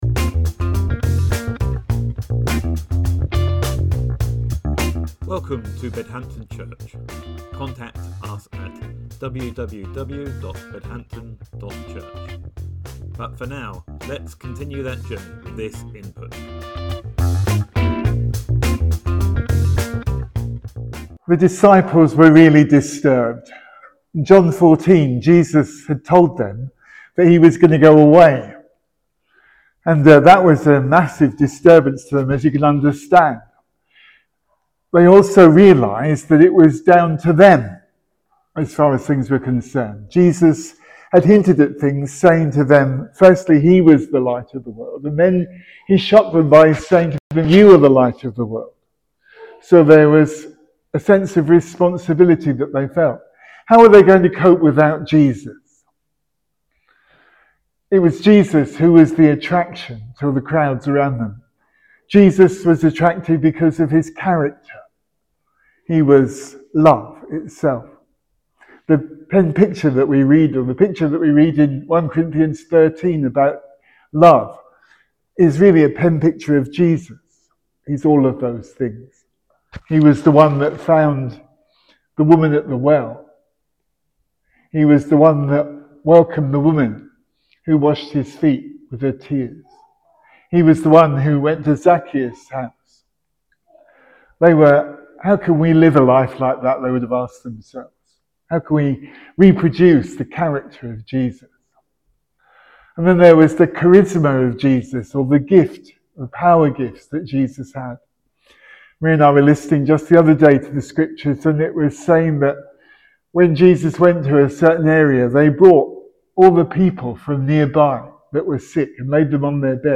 Sermon: A New Coat - Bedhampton Church